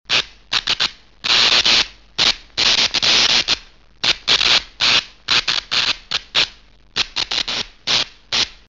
Звуки искры
На этой странице собраны разнообразные звуки искры: от ярких электрических разрядов до тихих потрескиваний.
5. Короткие замыкания в электросетях